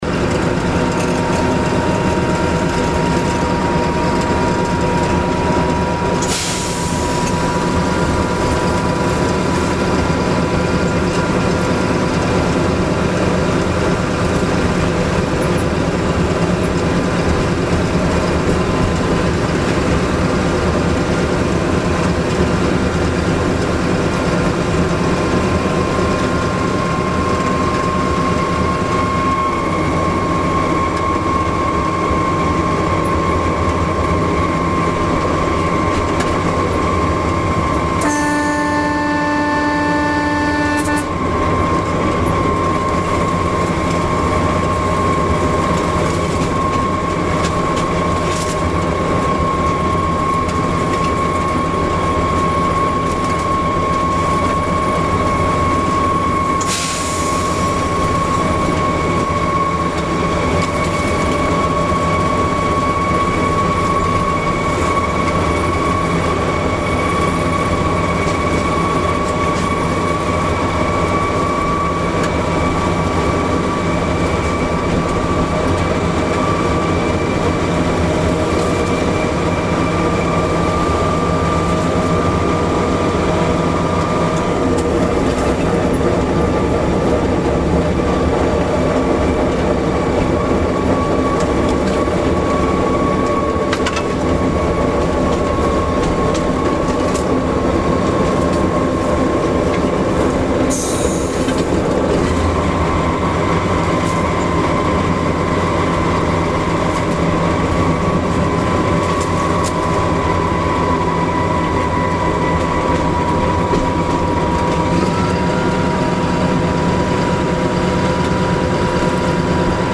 Lxd2-332 z lekkim sk�adem pokonuje wzniesienie w lesie jakubowskim - prosto z kabiny! (5:42 7,84 Mb .mp3 hifi stereo)